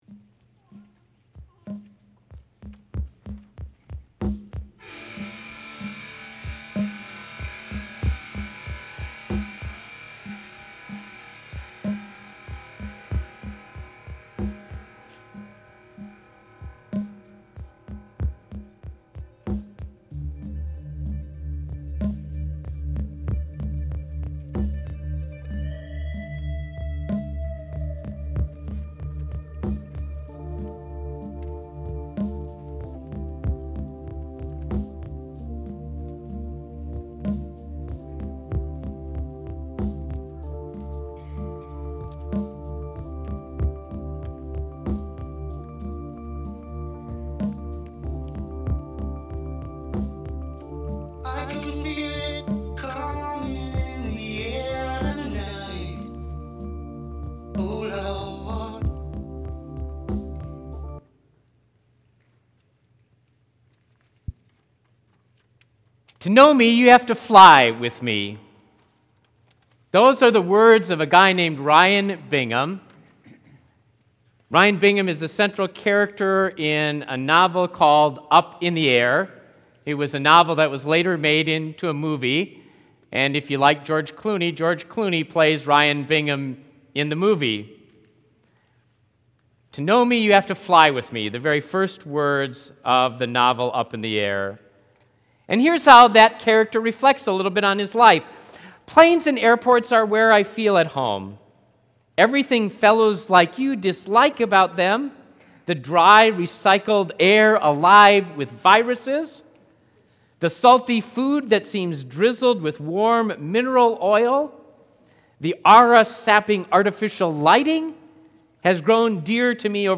Sermon preached April 12, 2015